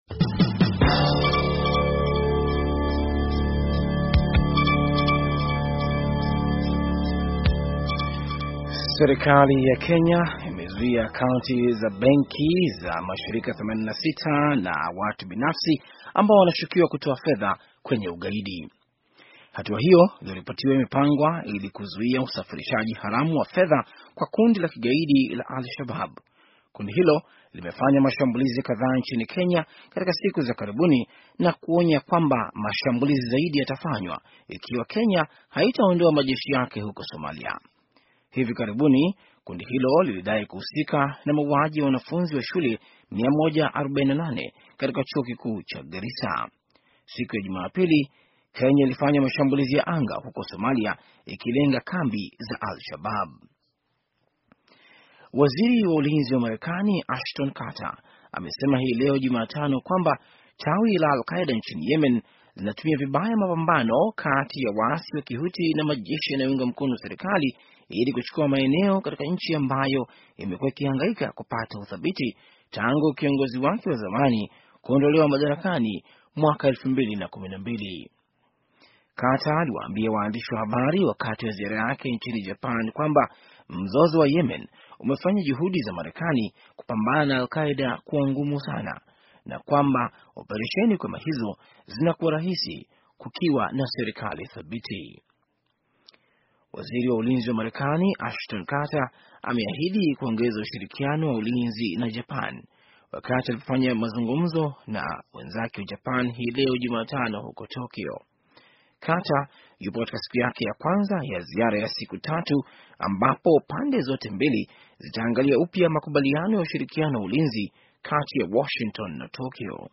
Taarifa ya habari - 4:47